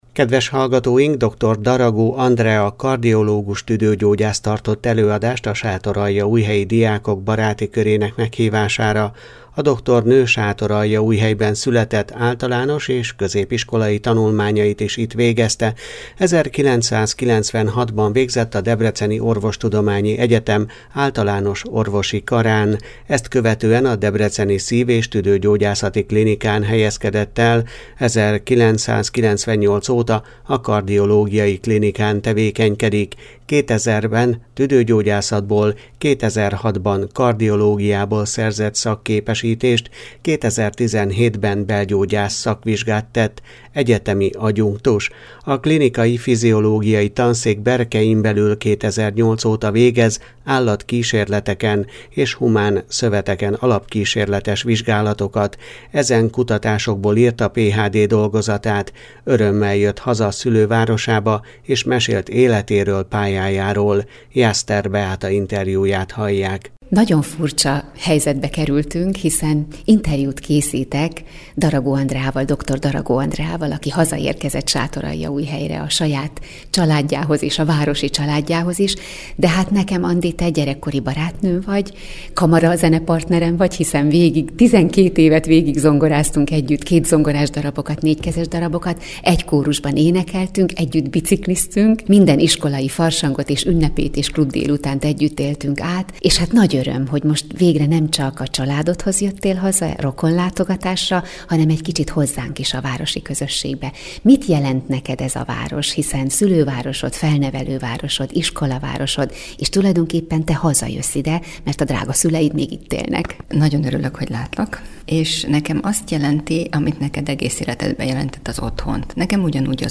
tartott előadást a Sátoraljaújhelyi Diákok Baráti Körének Meghívására